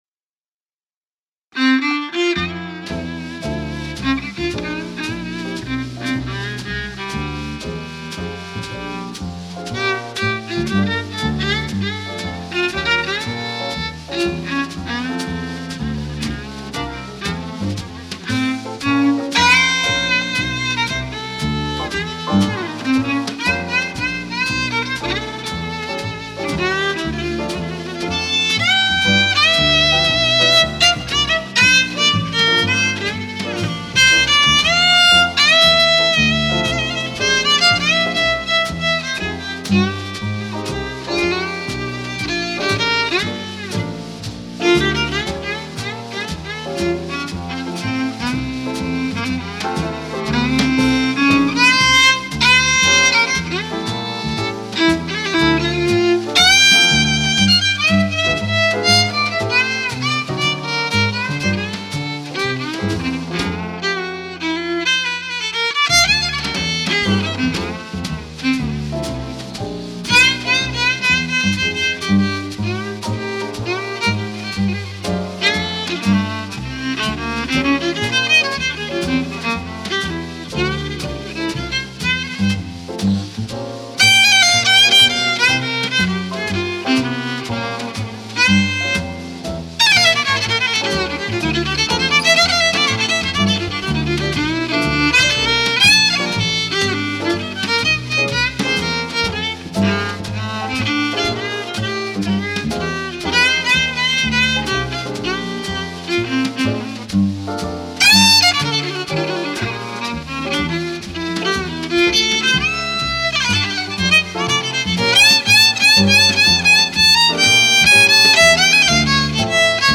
джазовых композиций